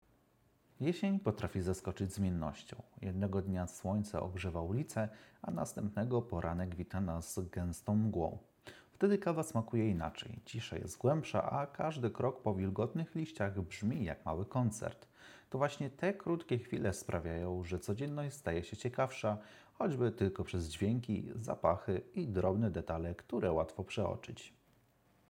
Głos brzmi naturalnie, ciepło, bez metalicznego posmaku, który zdarza się w tańszych modelach.
Próbka surowego dźwięku prosto z mikrofony – nagrana za pomocą iPhone 16 Pro Max: